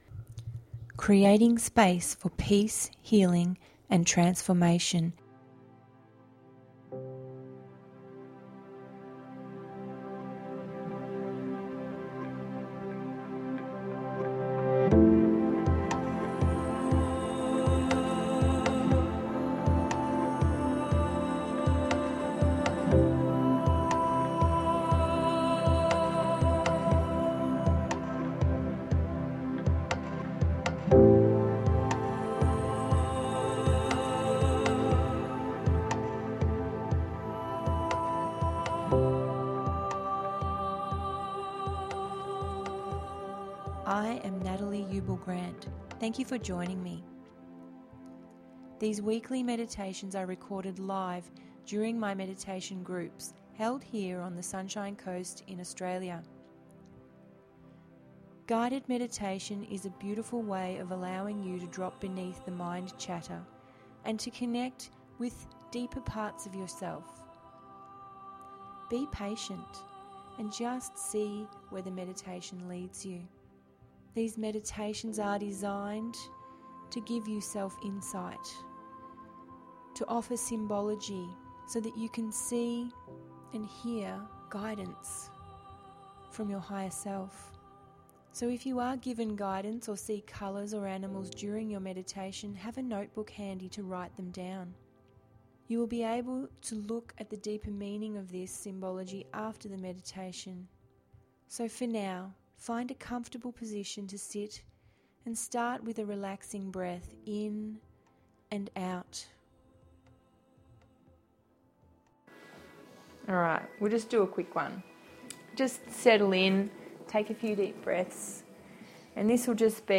093-simple-grounding-release.mp3